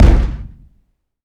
weapon_cannon_shot_03.wav